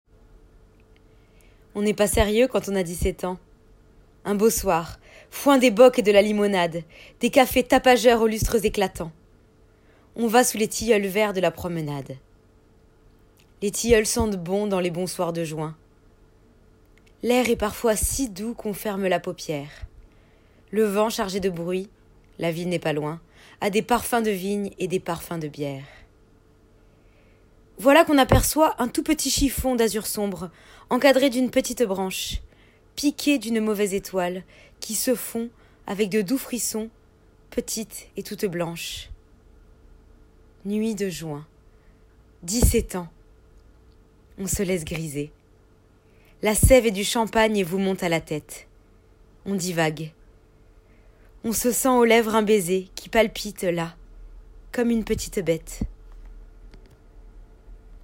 Enregistrement Poème Arthur Rimbaud
13 - 35 ans - Contralto